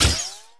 minig_fire_01.wav